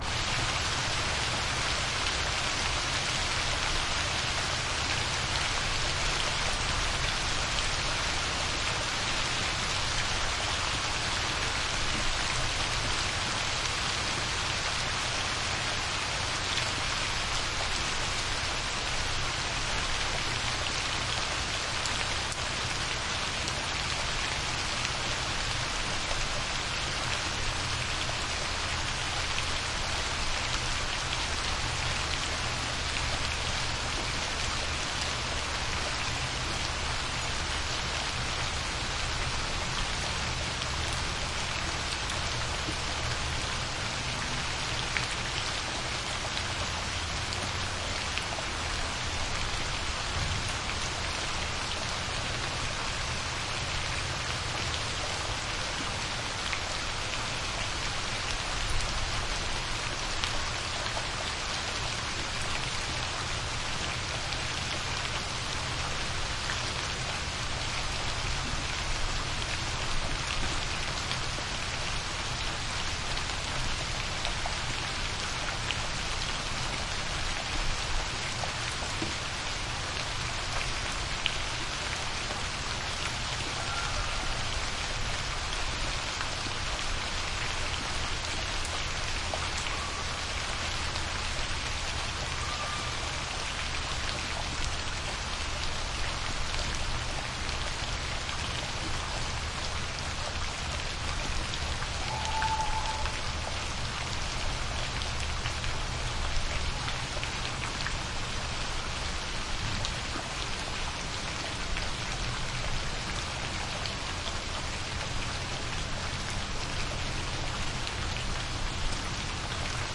营地 " 雨中的组与叶子软飞溅的营地 - 声音 - 淘声网 - 免费音效素材资源|视频游戏配乐下载
在与叶子软的splatty campground.flac的小组的雨媒介